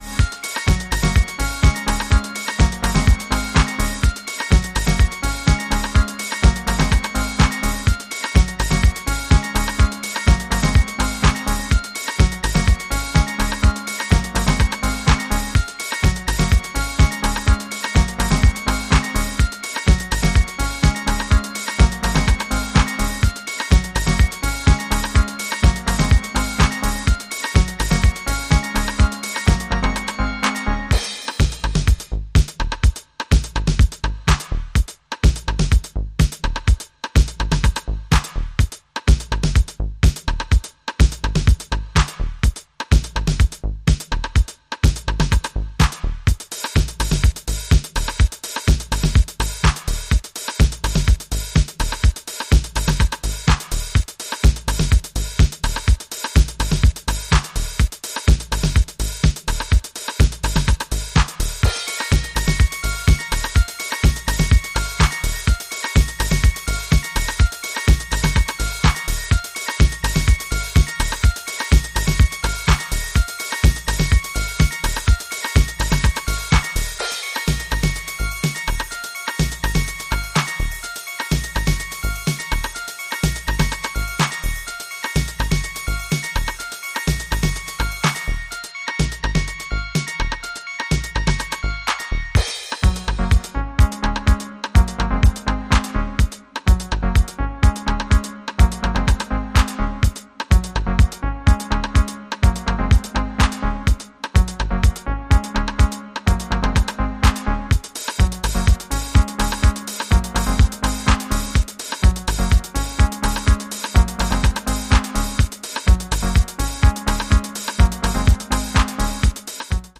New Release Deep House Disco House